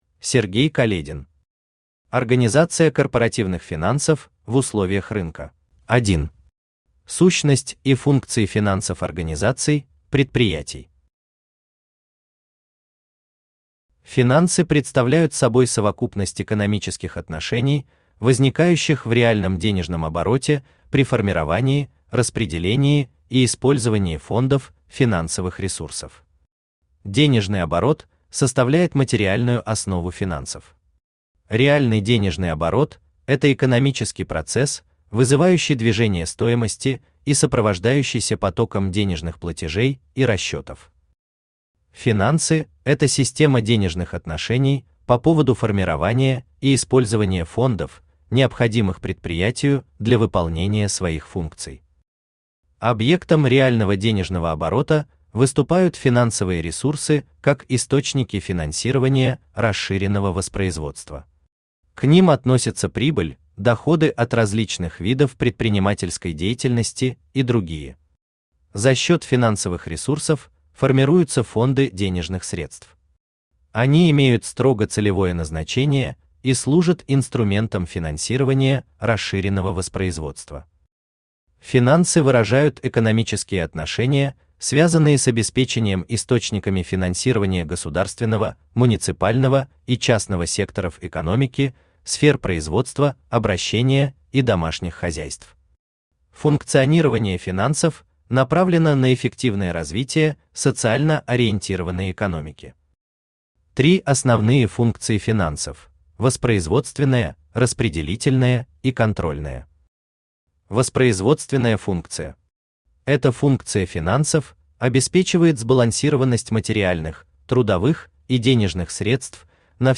Аудиокнига Организация корпоративных финансов в условиях рынка | Библиотека аудиокниг
Aудиокнига Организация корпоративных финансов в условиях рынка Автор Сергей Каледин Читает аудиокнигу Авточтец ЛитРес.